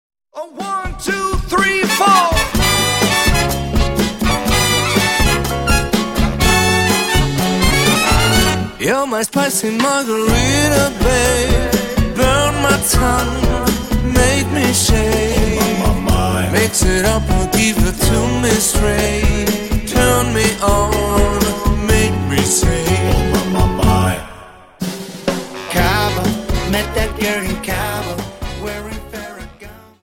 Dance: Cha Cha Solo Song